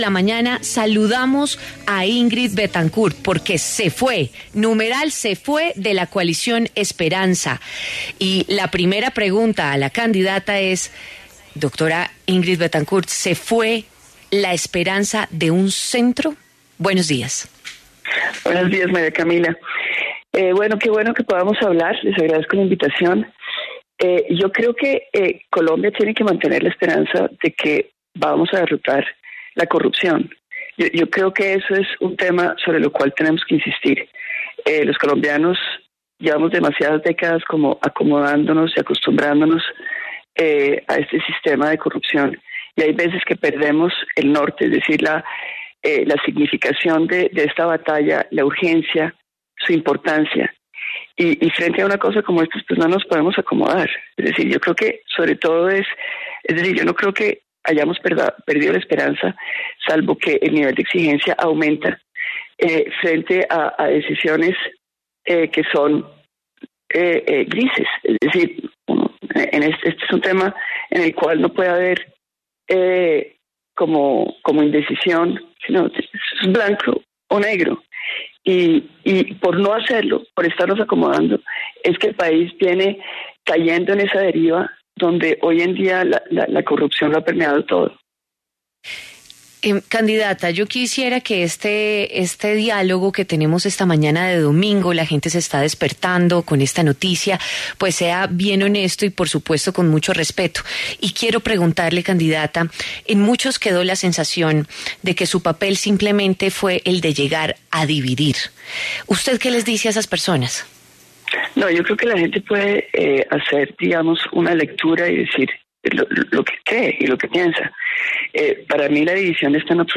Ingrid Betancourt, precandidata presidencial, habló en W Fin de Semana sobre su salida de la Coalición Centro Esperanza y respondió si los avalados de su pardito podrían incurrir en doble militancia.